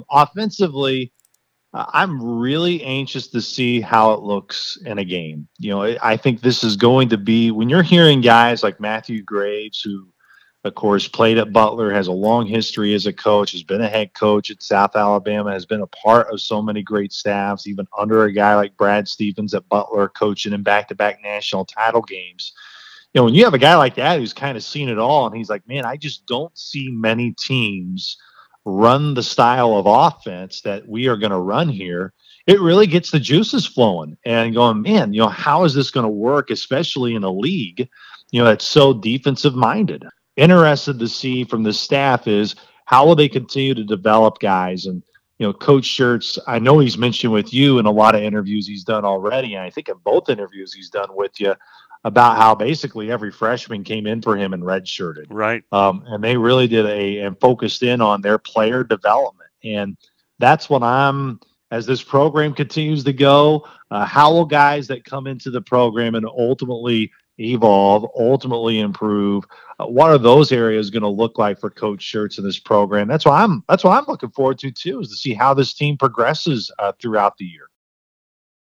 During our interview we discussed both the roster and challenges that lie ahead.